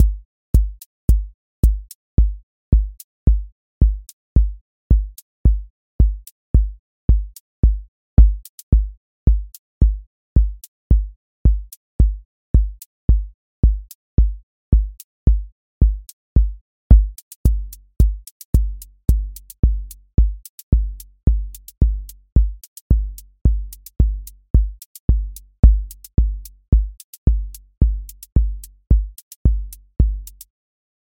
Four Floor Drive QA Listening Test house Template: four_on_floor April 18, 2026 ← Back to all listening tests Audio Four Floor Drive
voice_kick_808 voice_hat_rimshot voice_sub_pulse